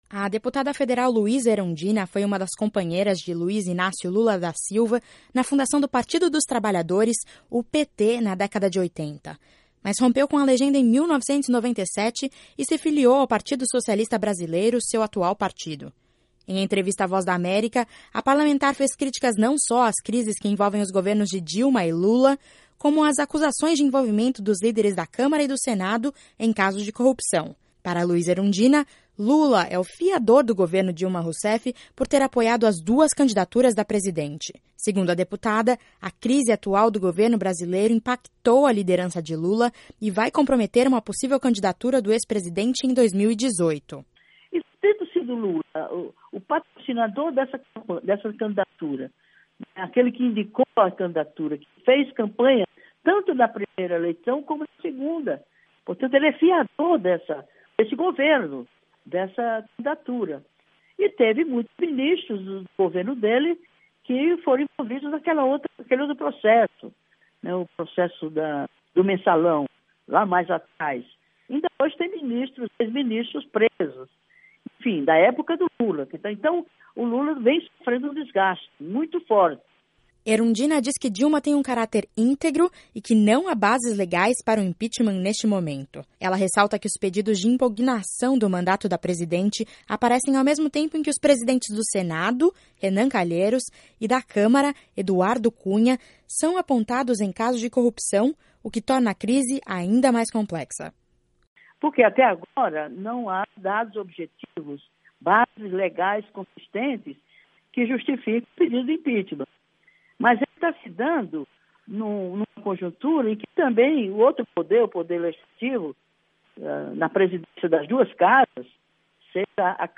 Em entrevista à Voz da América, a parlamentar fez críticas não só às crises que envolvem os governos de Dilma e Lula, como às acusações de envolvimento dos líderes da Câmara e do Senado em casos de corrupção.